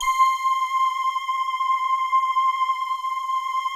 Index of /90_sSampleCDs/Optical Media International - Sonic Images Library/SI1_Soft Voices/SI1_ShortSftVoic